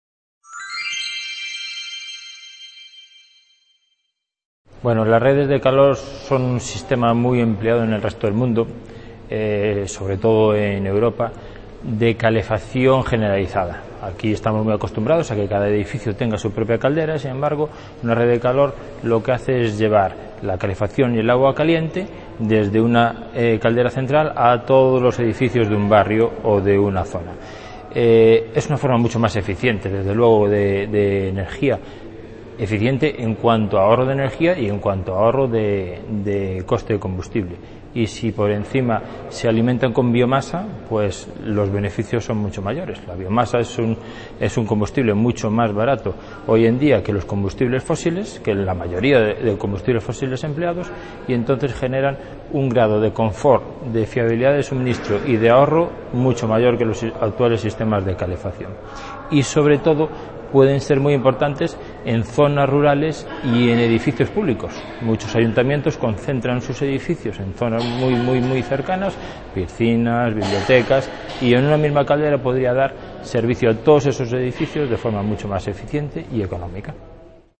Entrevista
C.A. Ponferrada - II Congreso Territorial del Noroeste Ibérico